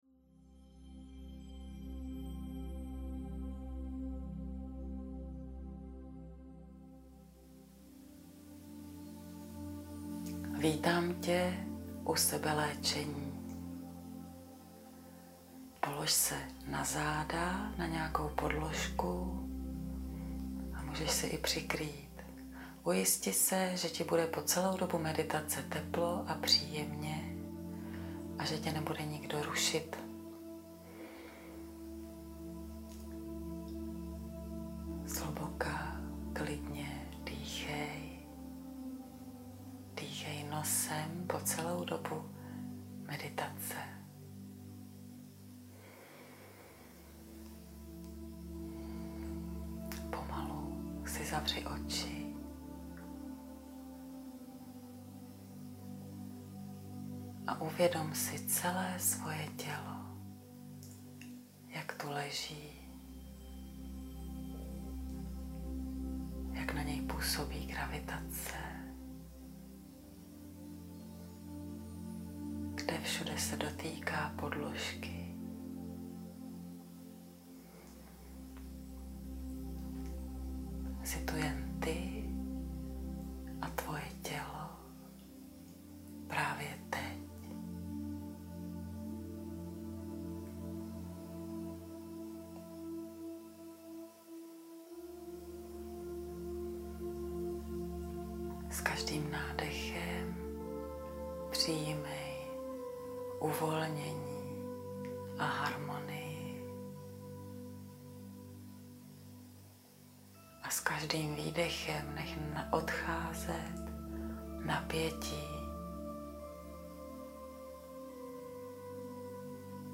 Meditace Sebeléčení